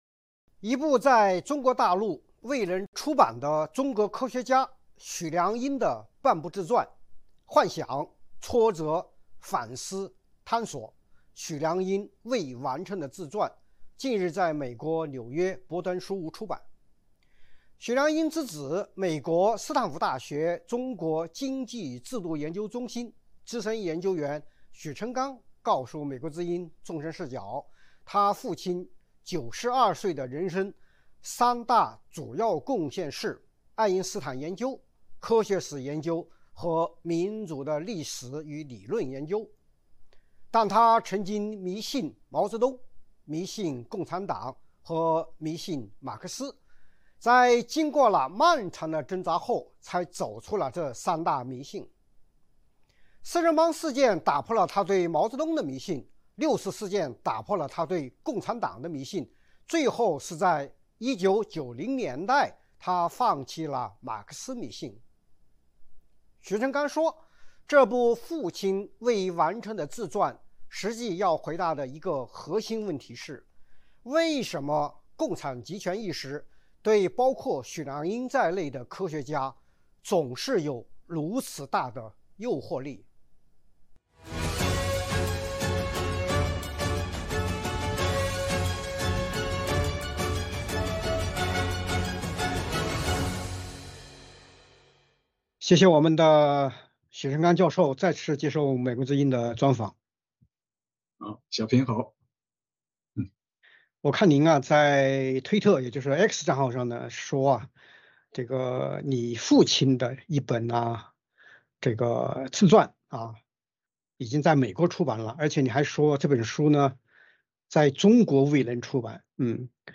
专访：许良英的幻想与挣扎：从迷信毛泽东共产党到回归爱因斯坦